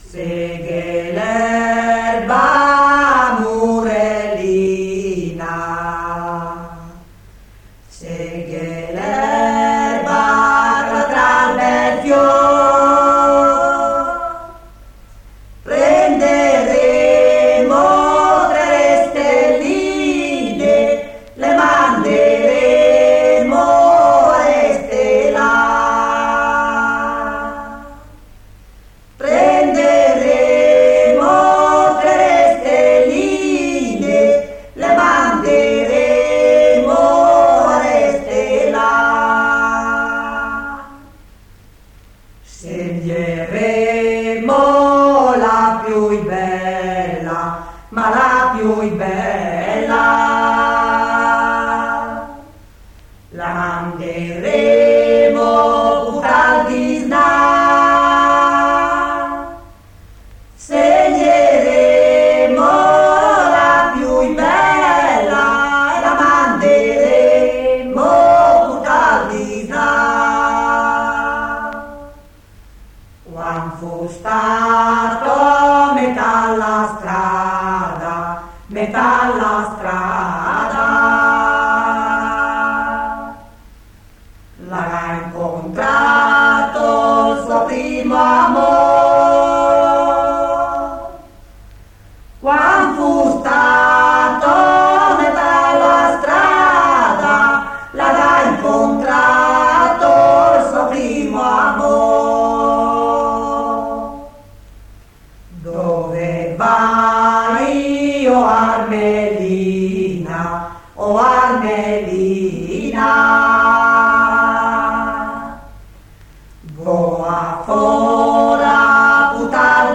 sono le eredi di una tradizione orale che tramanda la vita contadina attraverso il canto corale.
La terza sta a destra e racconta il vero di me, ed è quella che nel canto senti meno, perché il vero di me è l’amore.
Il canto mondino per terze è una grande invenzione, perché non sente padroni e scivola e devia come vuol lui. Non è temperato, non segue codice, è anarchico e fiero, è tutto quel che noi vorremmo essere e non siamo. Son tre binari paralleli che non chiudono mai, non risolvono come nella musica per signori.